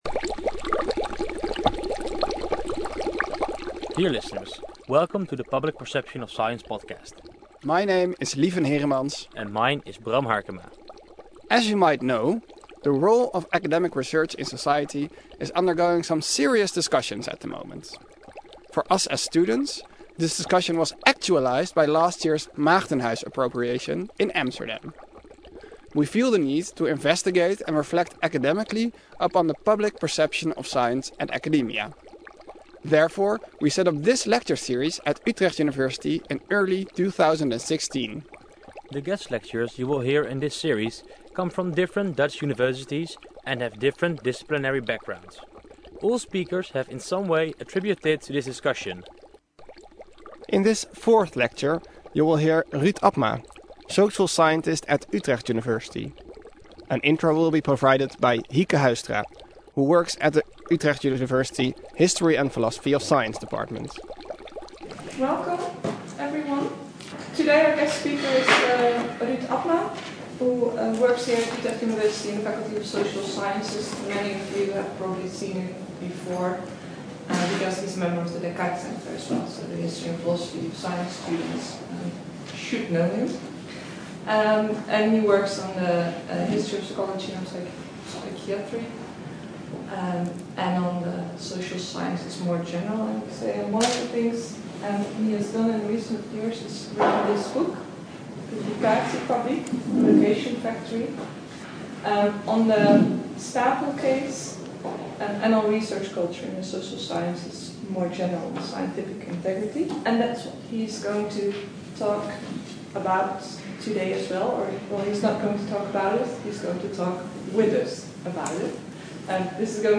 Science largely rests on the integrity of scientists and that is not always a secure foundation. For some, fraud and plagiarism are an irresitable tempation on your way to fame. In this lecture